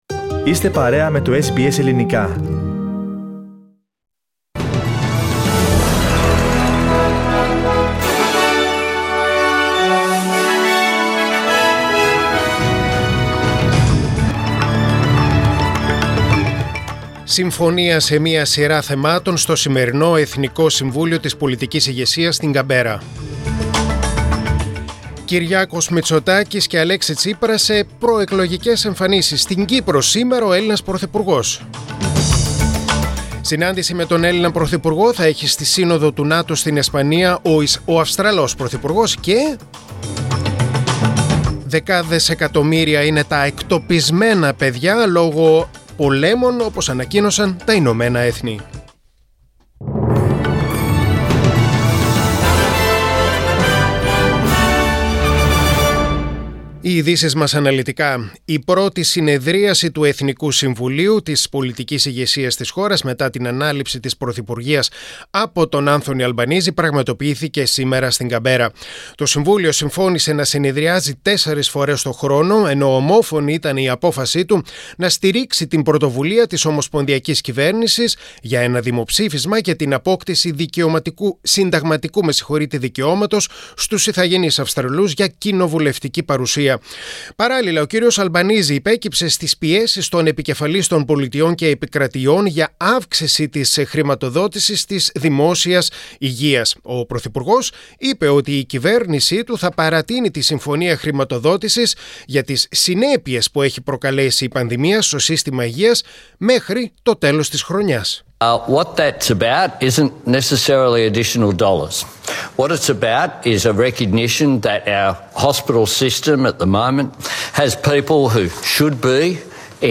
Δελτίο Ειδήσεων: Παρασκευή 17.6.2022